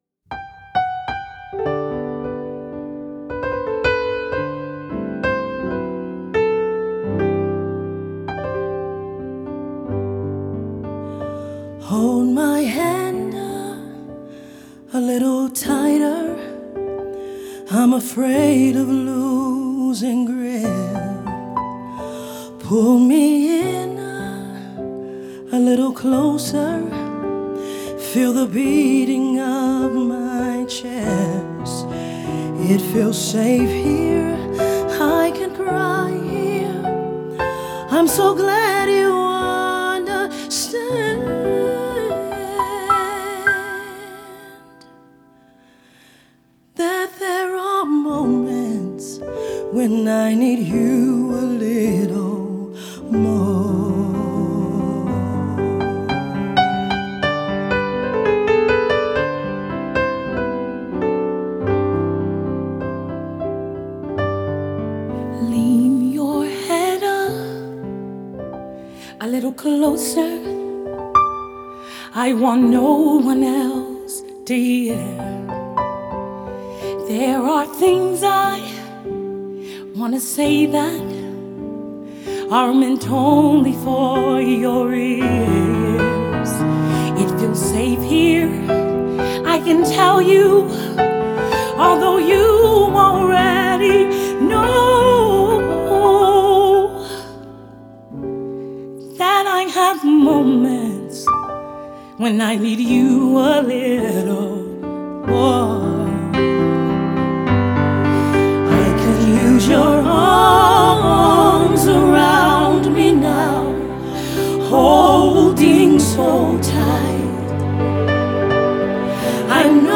장르: Funk / Soul, Pop
스타일: Gospel, Vocal